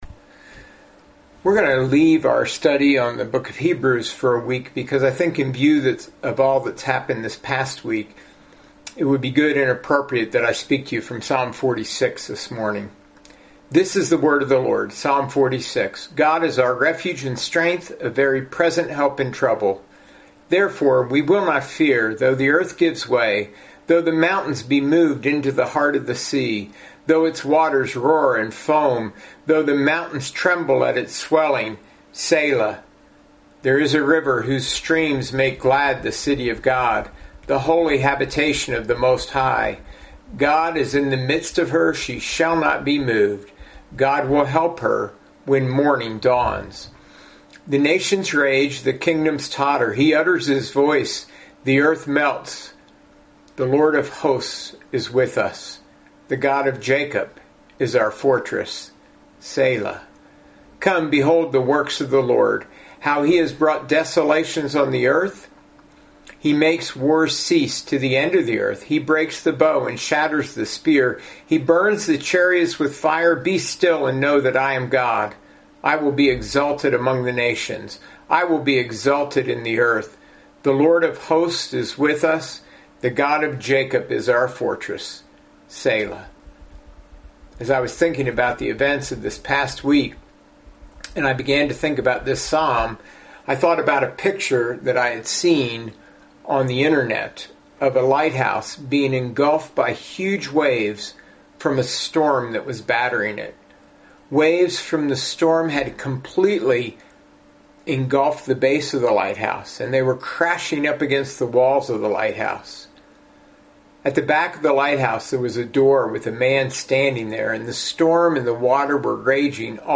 Sermons — Foothills Community Church